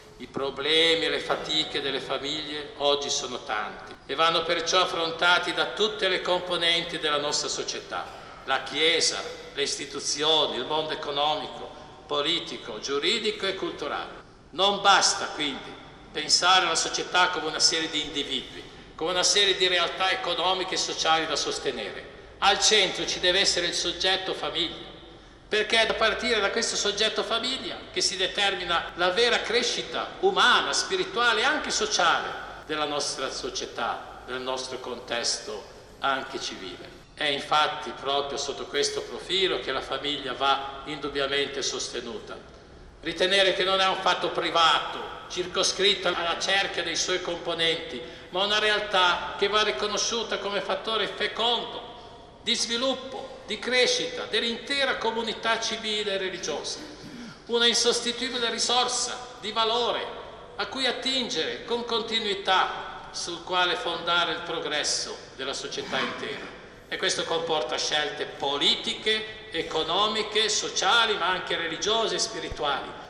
Festa della Patrona dell'Arcidiocesi: Messa e processione con l'Arcivescovo - Diocesi di Torino